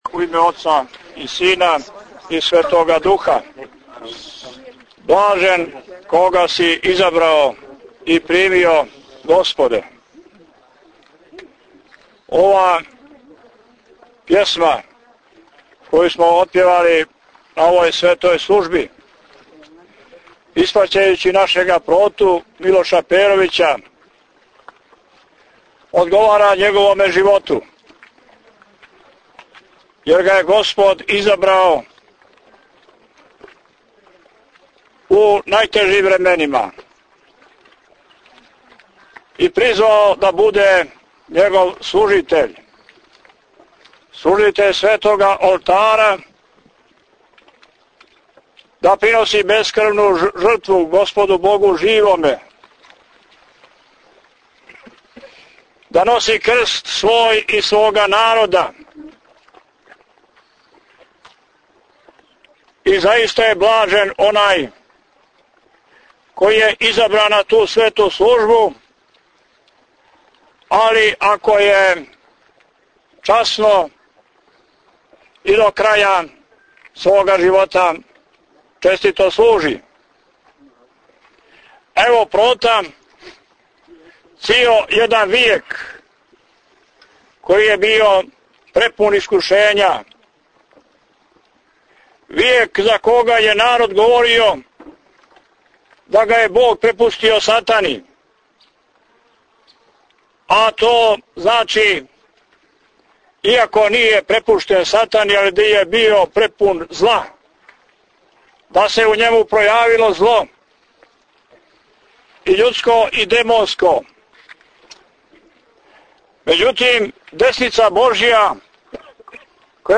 Опијело је служио Његово Преосвештенство Епископ будимљанско-никшићки Г. Јоаникије са бројним свештенством Преузмите аудио датотеку 581 преузимања 56 слушања Прочитајте више